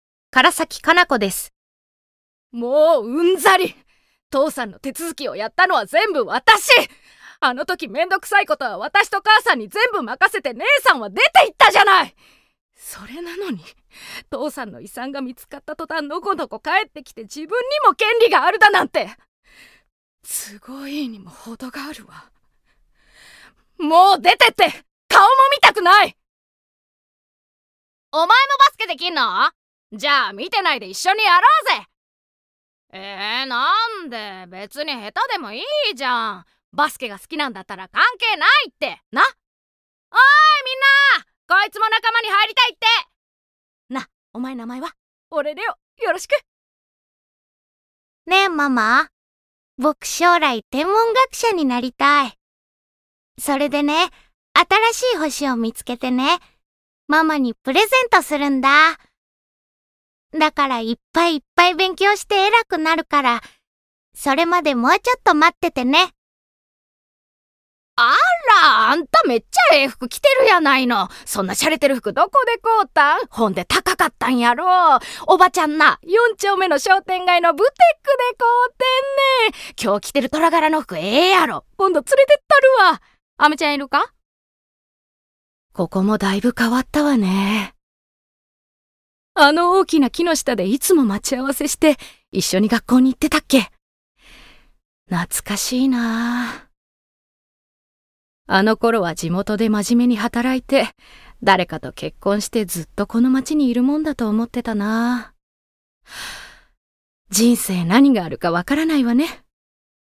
方言　 ： 関西弁
◆台詞